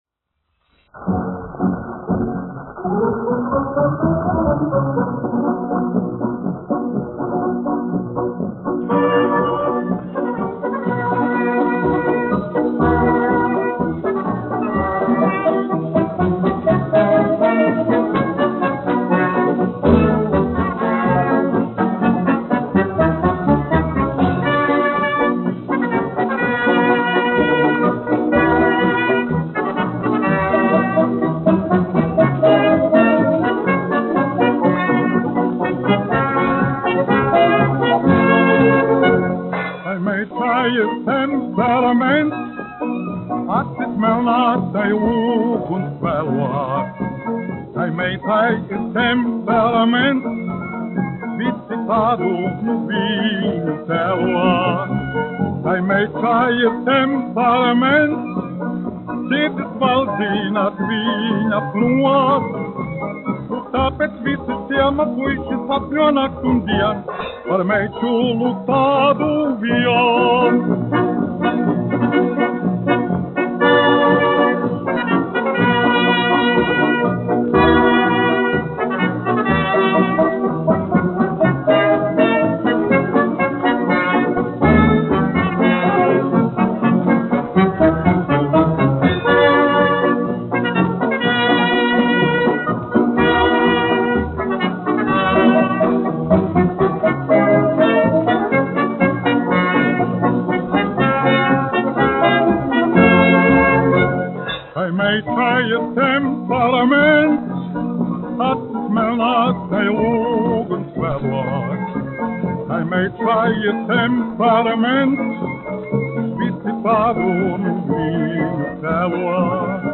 1 skpl. : analogs, 78 apgr/min, mono ; 25 cm
Fokstroti
Populārā mūzika
Skaņuplate
Latvijas vēsturiskie šellaka skaņuplašu ieraksti (Kolekcija)